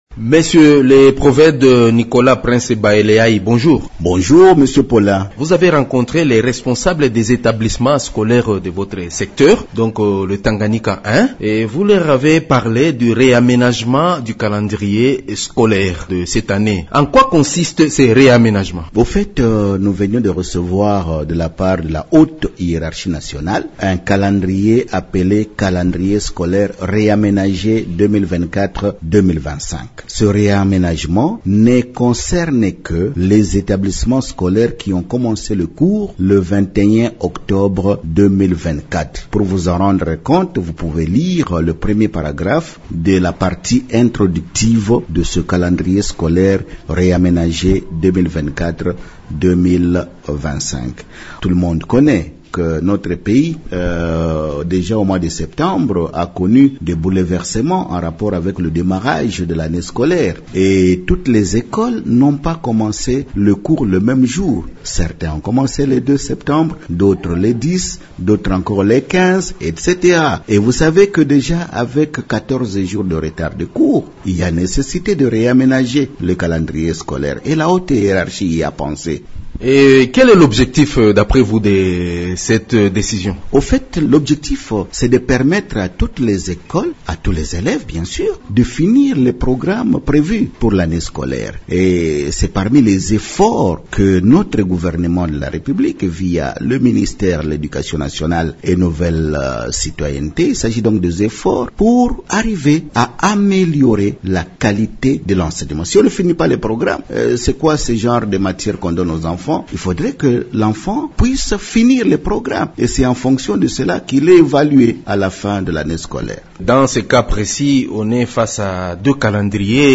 Dans un entretien avec Radio Okapi, ce responsable de l’Education nationale a ajouté qu’avec ce nouveau calendrier scolaire, les élèves dont les enseignants étaient en grève iront en vacances de Noel à partir du 27 décembre prochain.